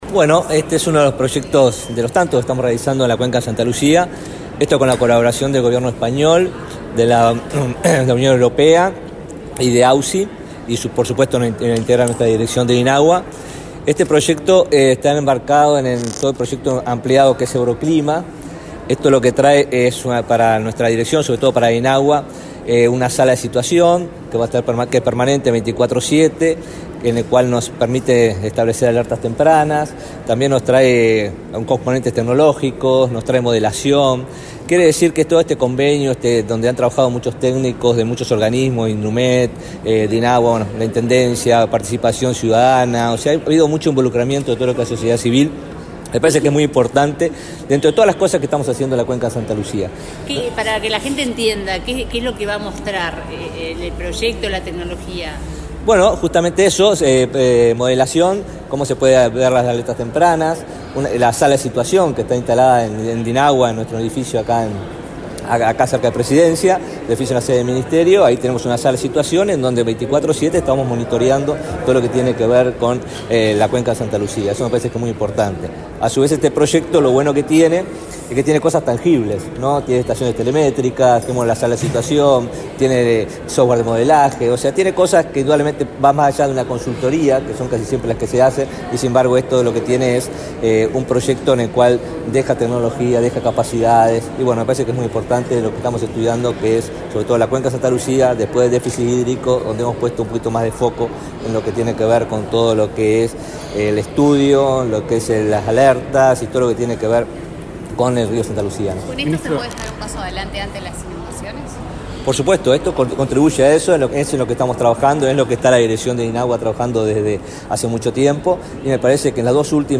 Declaraciones del ministro de Ambiente, Robert Bouvier
Este lunes 8 en la Torre Ejecutiva, el ministro de Ambiente, Robert Bouvier, dialogó con la prensa, antes de participar en la presentación de los resultados del proyecto implementado en la cuenca del Santa Lucía “Tecnología y modelación para la gestión integrada de las aguas como adaptación al cambio climático de la principal fuente de agua potable de Uruguay”.